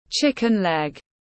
Chân gà tiếng anh gọi là chicken leg, phiên âm tiếng anh đọc là /ˈʧɪkɪn lɛg/
Chicken leg /ˈʧɪkɪn lɛg/